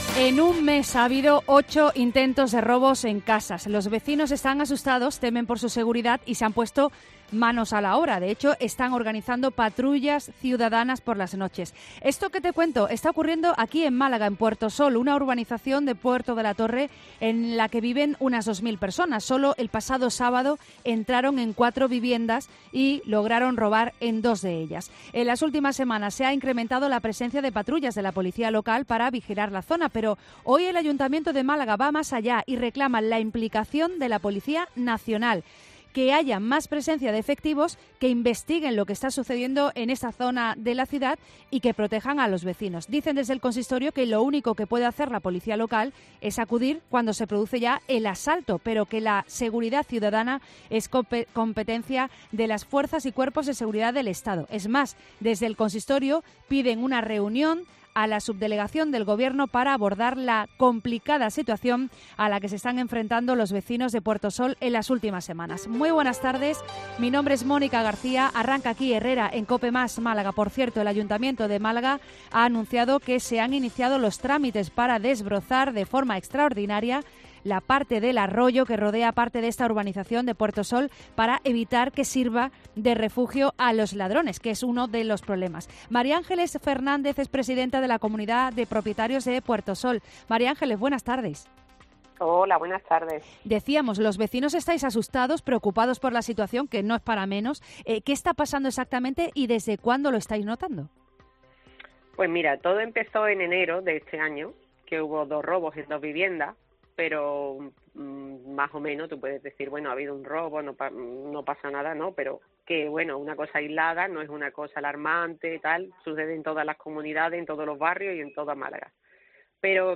Una vecina de Puertosol ante la oleada de asaltos en casas: “Entran en tu parcela estando tú dentro"